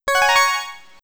Écoutons d’abord les sons joués lorsque le joueur s’empare d’une ressource sur la carte.
Certains sons, plus stridents font penser à une pluie d’or, tandis que d’autres ont une profondeur (grâce à la réverbération et la longueur du son) que l’on ne peut trouver que dans certaines pierres précieuses ; certains font penser à une forme brute et anguleuse (avec une attaque très marquée) tandis que d’autres ont une forme de rondeur et de douceur qui peuvent évoquer la perle.